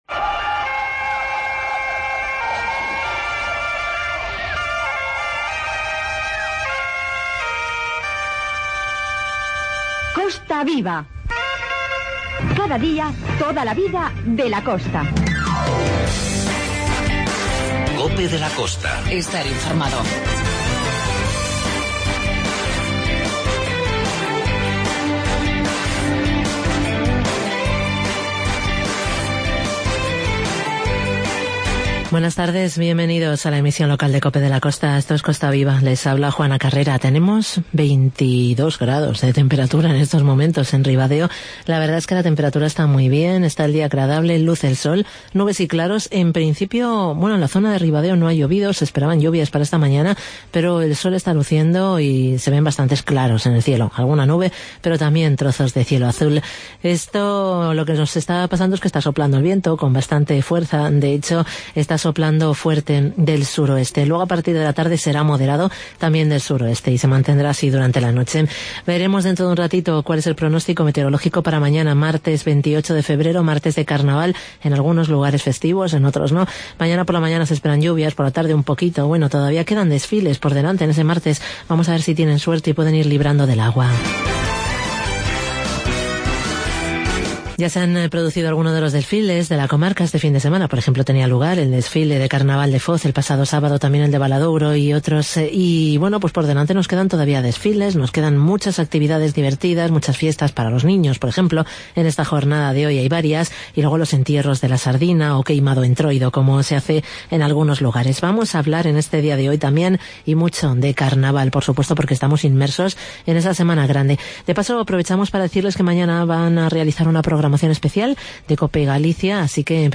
Informativo "Costa Viva"